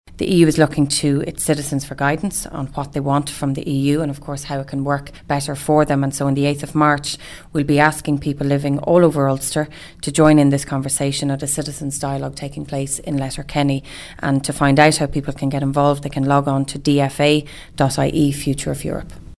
EU Affairs Minister Helen McEntee will be in attendance and says the format of the event will give individuals an opportunity to express their views: